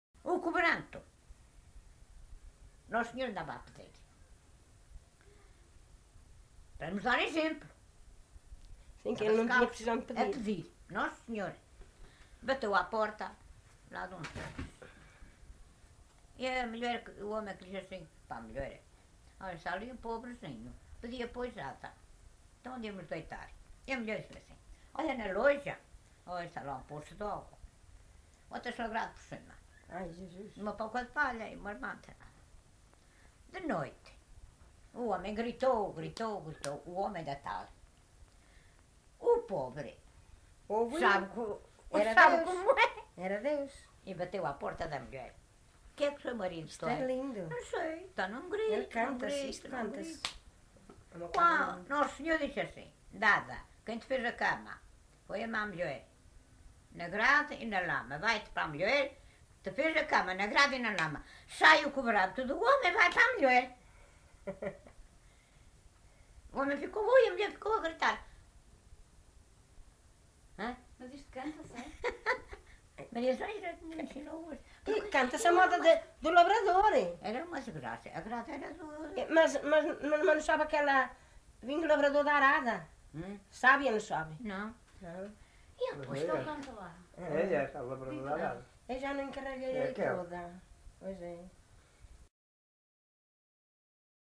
LocalidadeGranjal (Sernancelhe, Viseu)